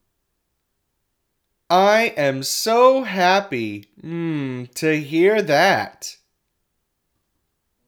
English_Emotional_Speech_Data_by_Microphone